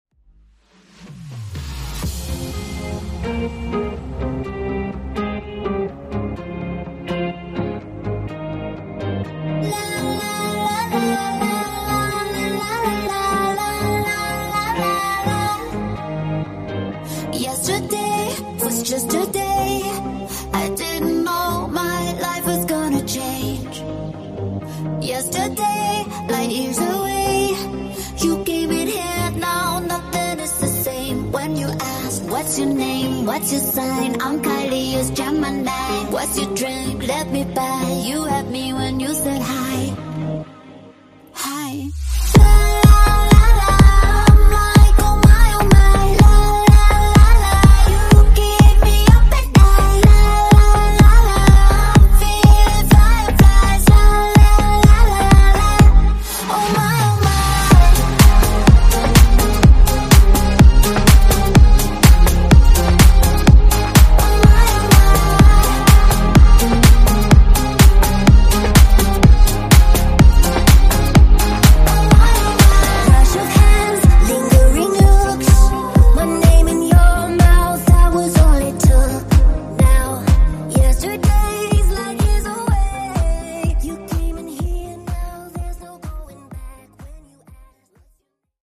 Genre: RE-DRUM Version: Clean BPM: 64 Time